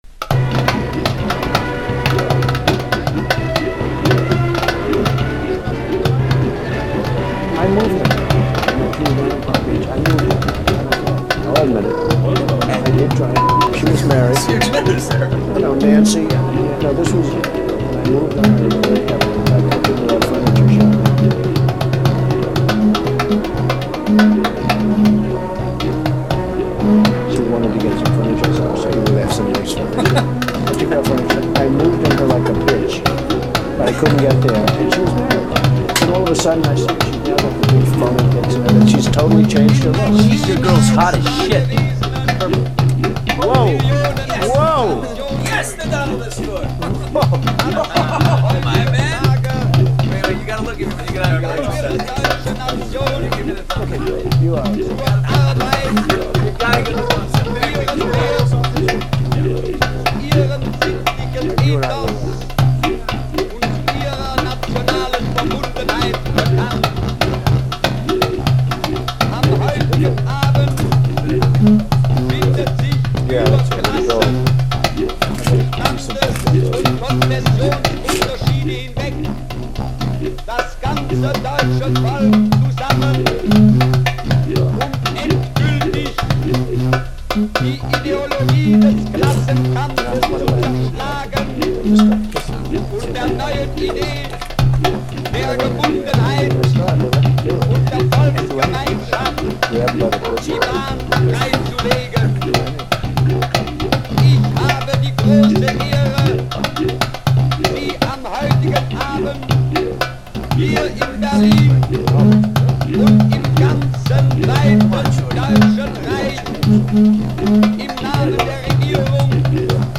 Genre : Experimental